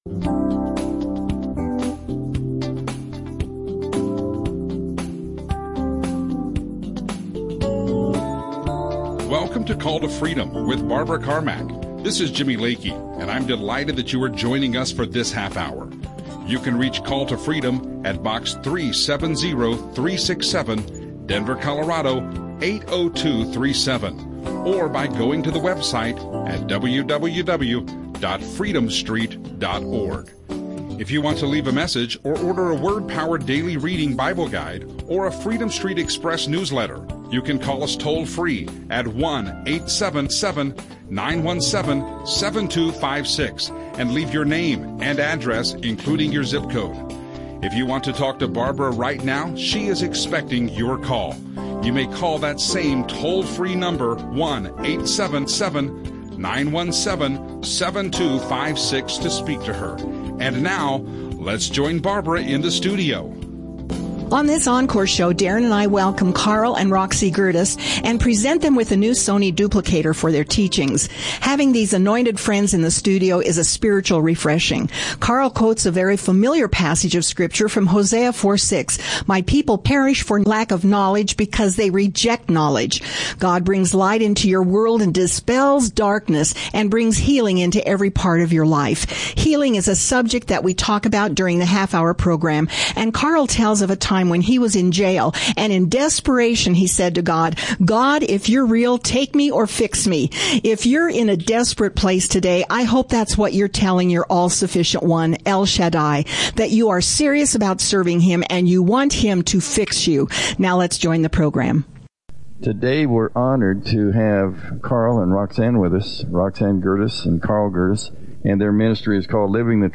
The four Spirit-filled speakers talk about the manifestation of miracles and healing on this earth.
Christian radio